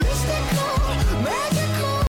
Mystical Magical Sound Button - Free Download & Play
Sound Effects Soundboard19 views